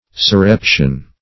Surreption \Sur*rep"tion\, n. [L. surreptio, or subreptio.